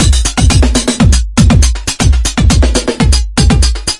描述：ssb语音传输与载波切口消除尖叫声。
Tag: 语音 广播 AM 信号 通信 传输 通讯科